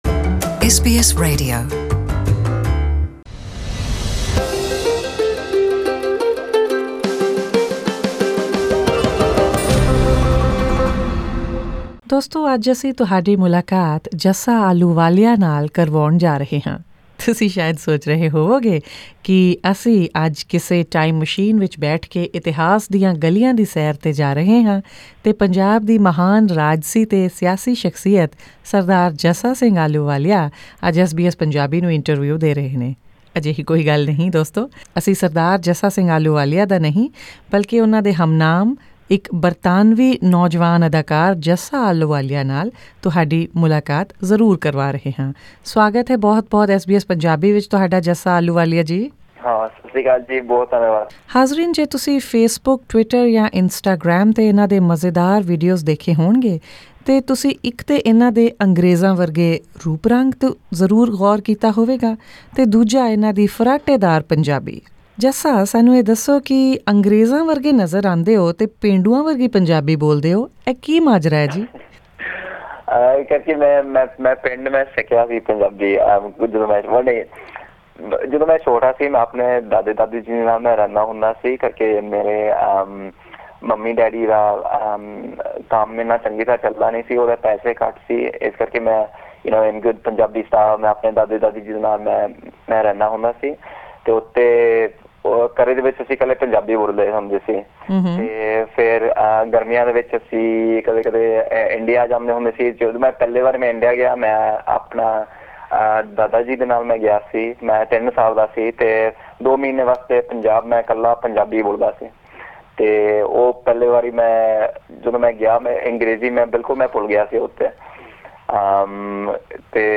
If you listen to Jassa Ahluwalia speak Punjabi with your eyes closed, there’s every chance you’ll assume he is fully desi.